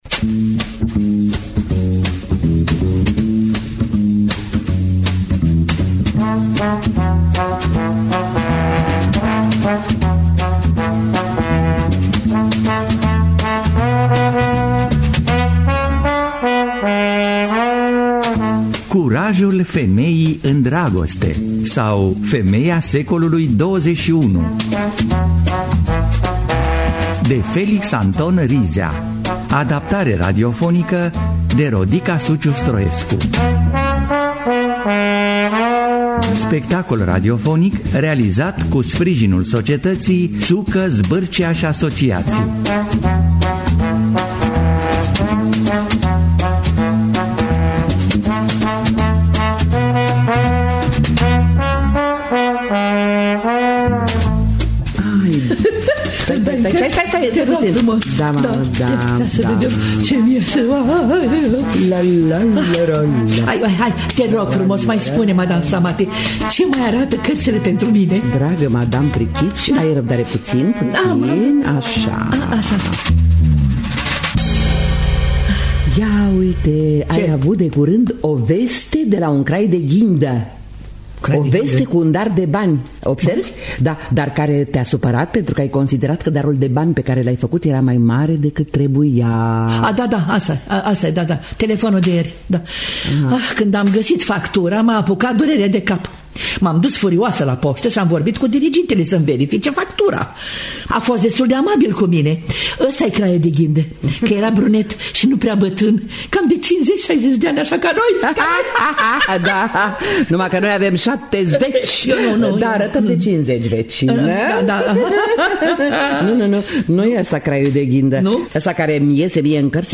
Adaptare radifonică
Curajul Femeii In Dragoste Sau Femeia Secolului Xxi- De Felix Anton Rizea O Comedie Suculenta.mp3